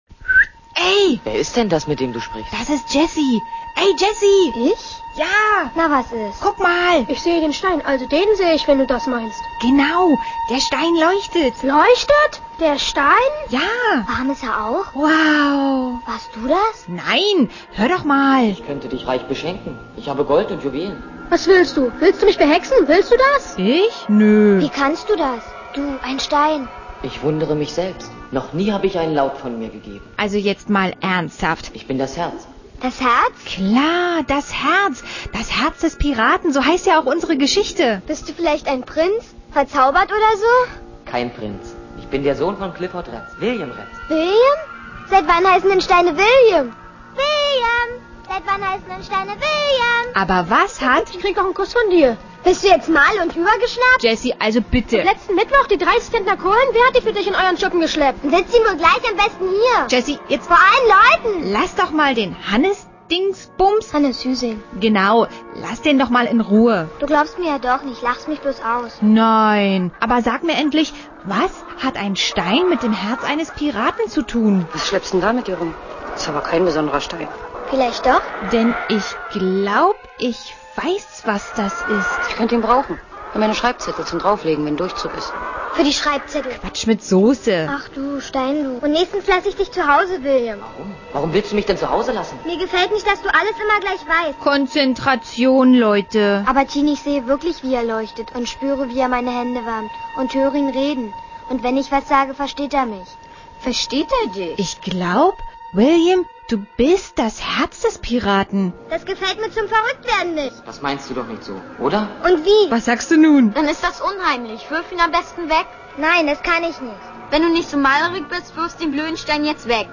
Audio-Trailer